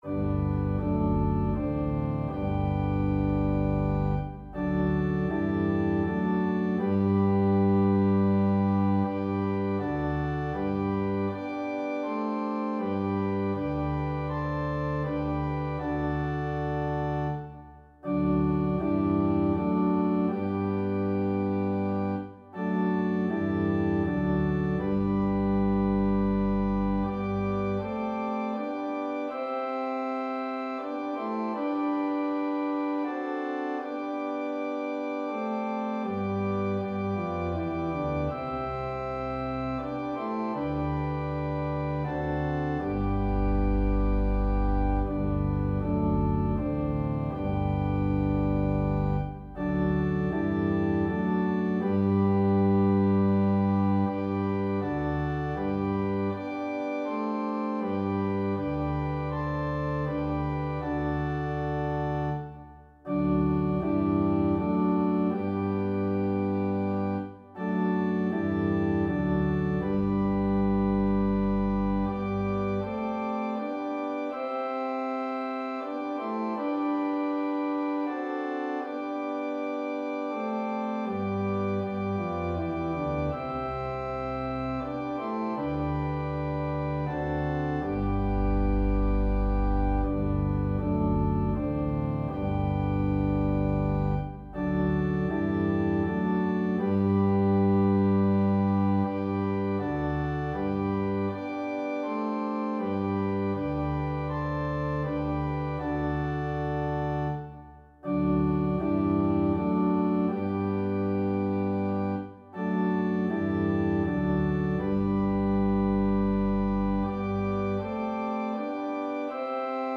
Click the Button to sing the prayer in G, or play the song in a New Window